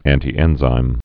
(ăntē-ĕnzīm, ăntī-)